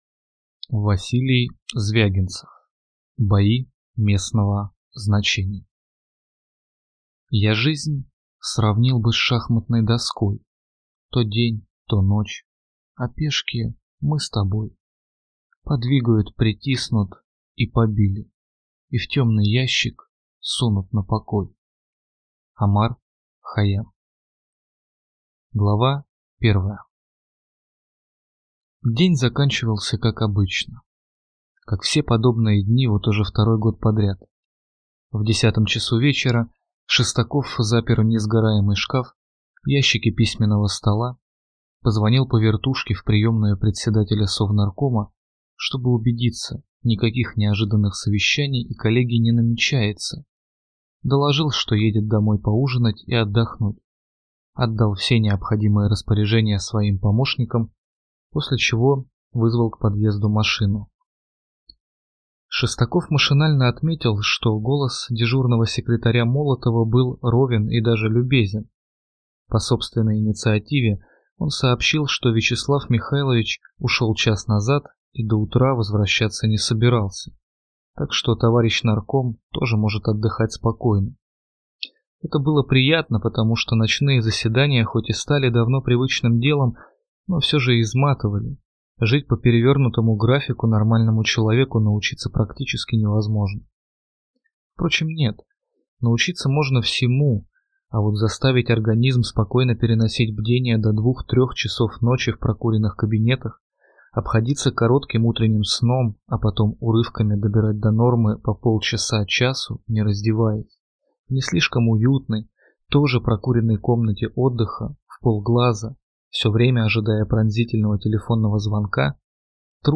Аудиокнига Бои местного значения | Библиотека аудиокниг